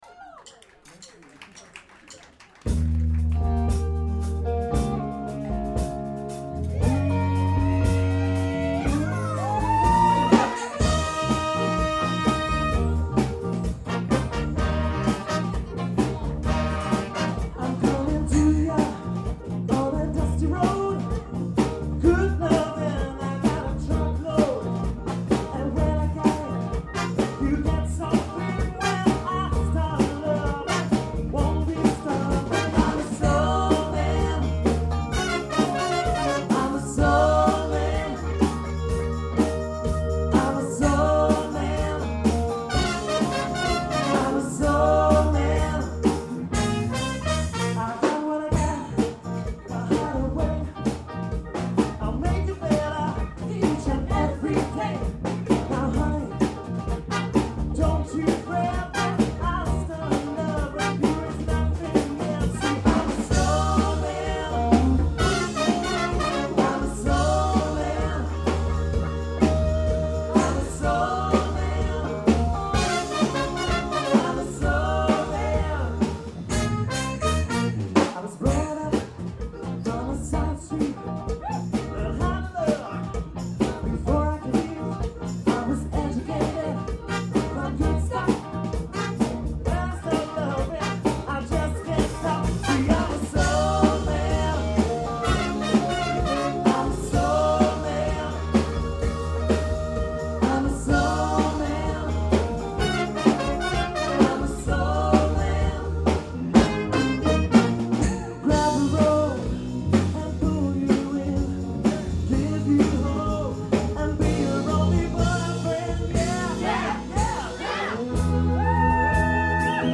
Big  Band - Rythm'n Blues